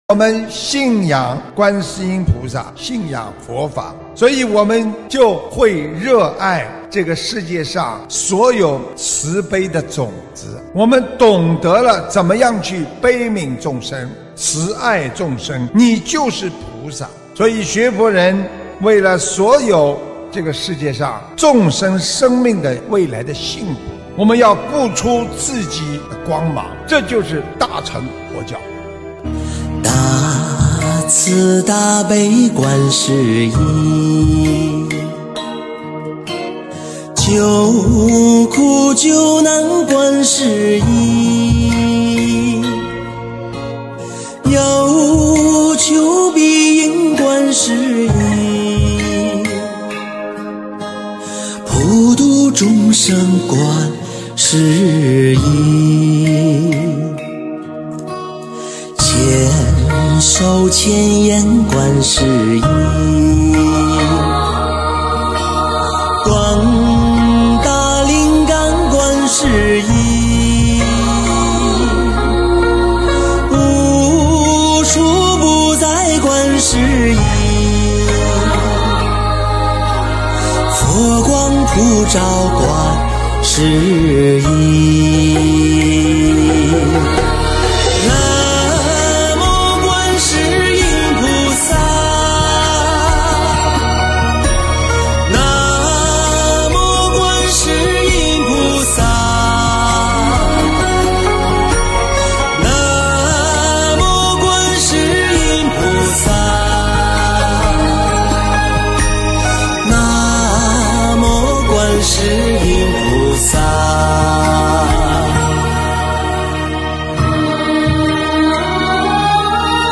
音频：德国慕尼黑慈心素食交流分享会花絮！2022年10月10日